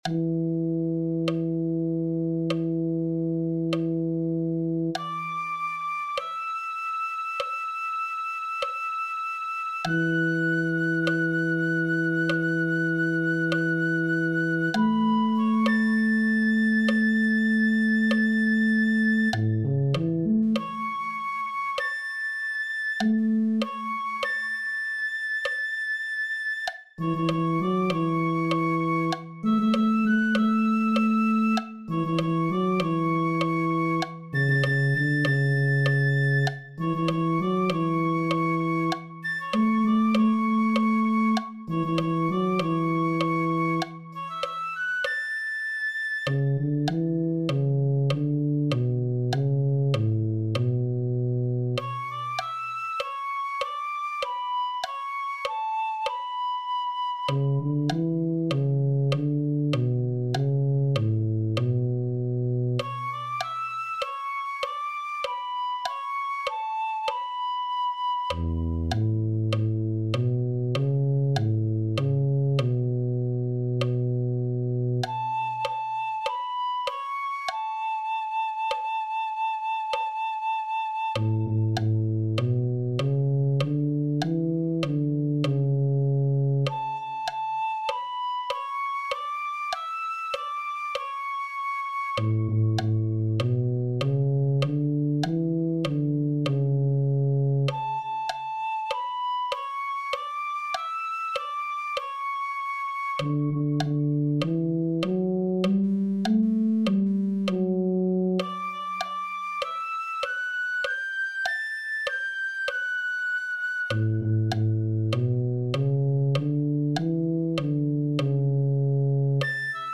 This arrangement is for tuba and piccolo.